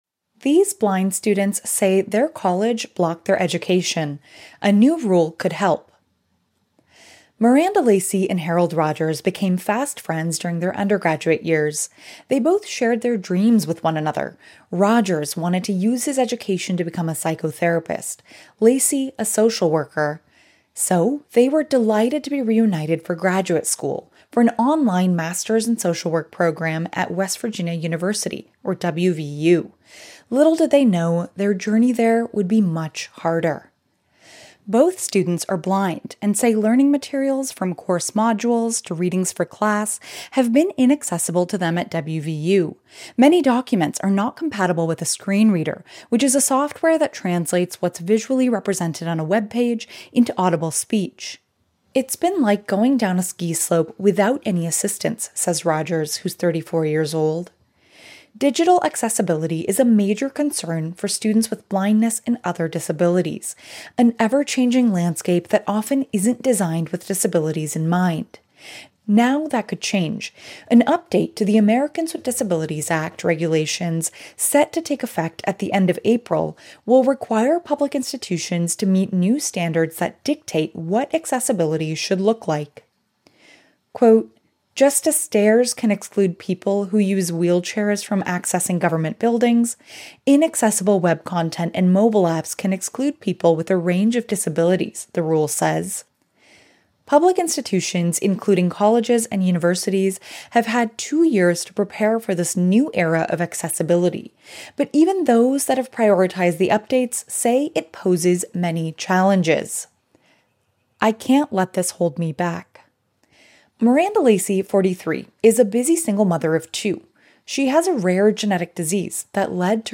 Accessible audio version